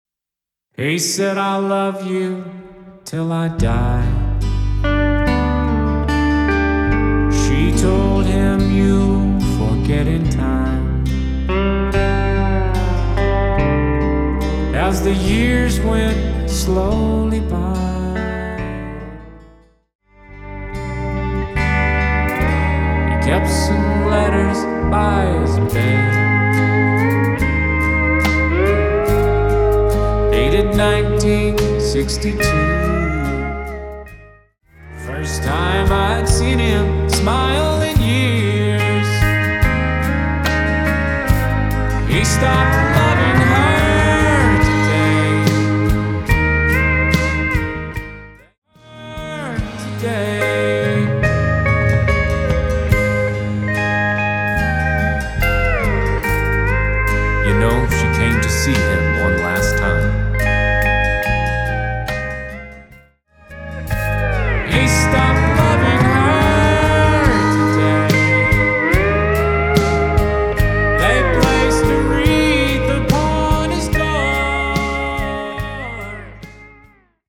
Song Preview: